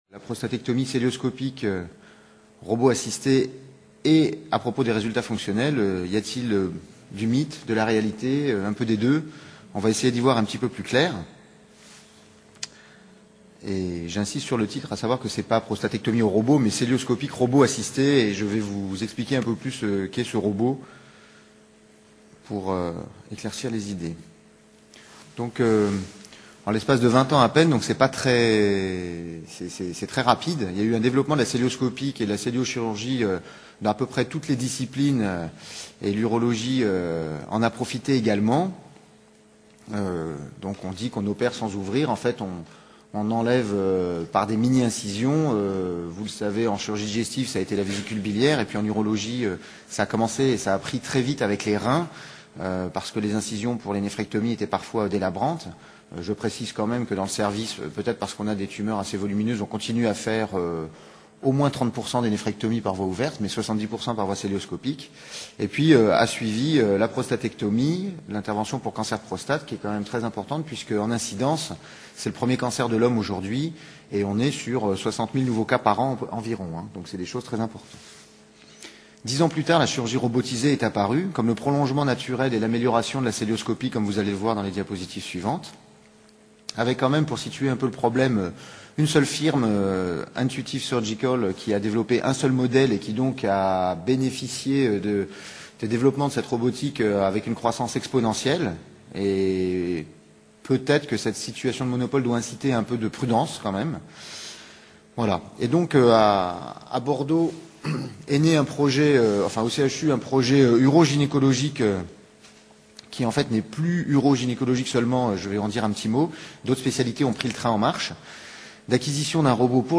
Journées Victor Segalen - Université Bordeaux 2 Formation Médicale Permanente : La Journée des Généralistes Organisée dans le cadre des Journées Victor Segalen 2010 par l’Unité Mixte de Formation Continue en Santé de l’Université Bordeaux 2, cette formation s’adresse avant tout aux médecins généralistes.